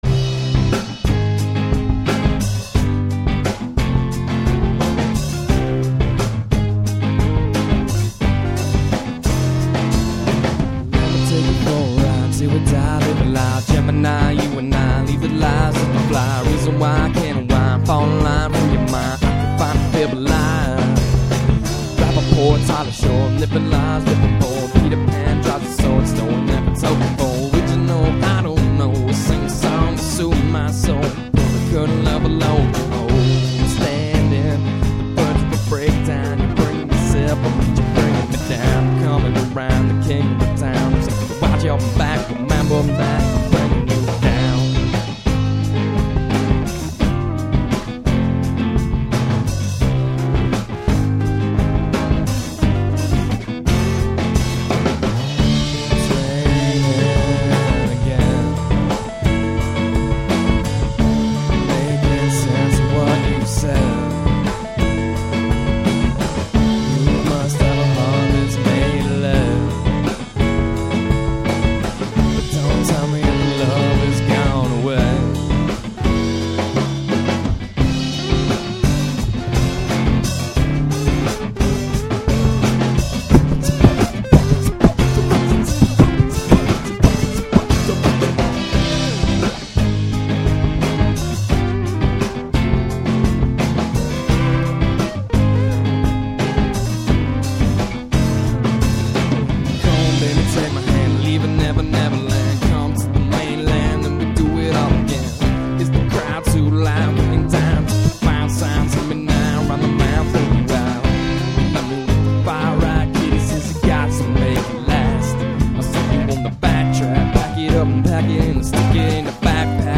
Bass
Guitar
Vocals